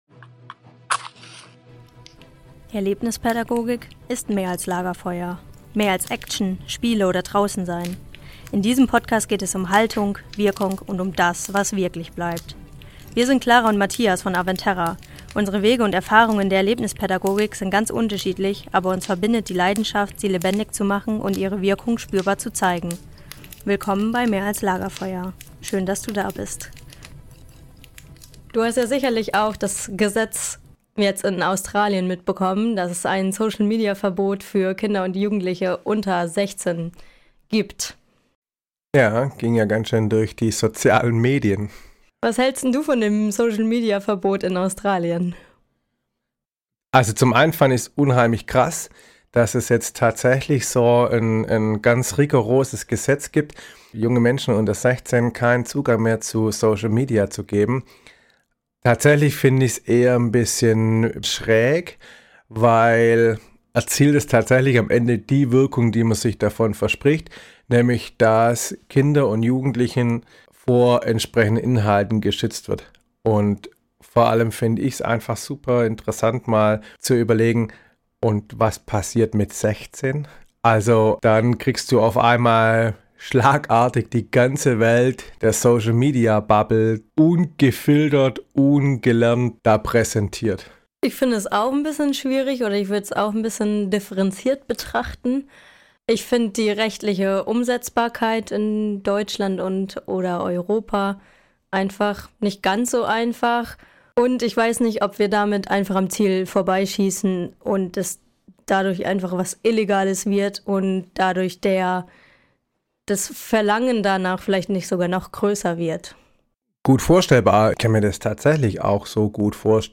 Eine ehrliche Diskussion über Medienkompetenz, Teilhabe und pädagogische Verantwortung im digitalen Alltag.